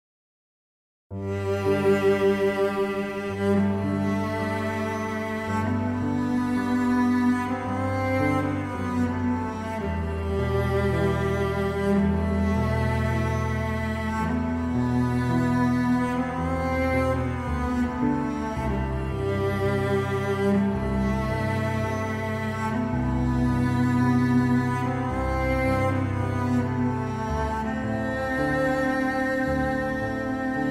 Heavenly Violin & Cello Instrumentals